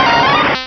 Cri de Boustiflor dans Pokémon Rubis et Saphir.